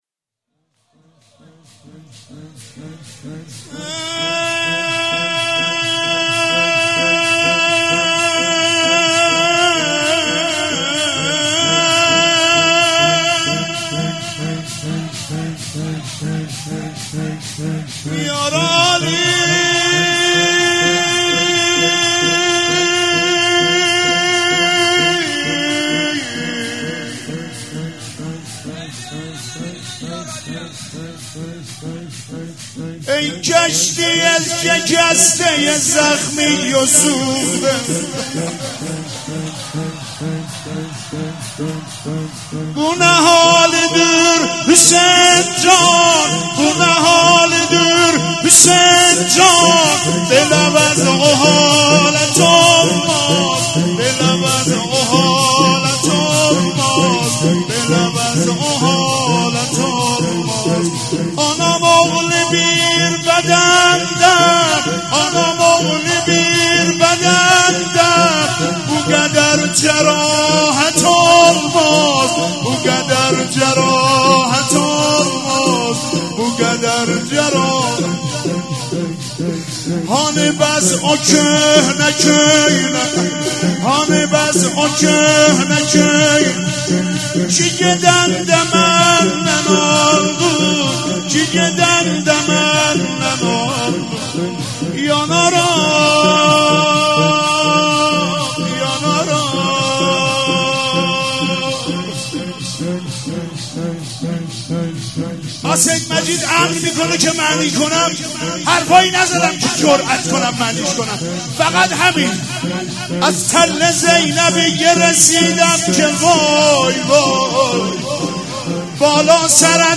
روضه سنگین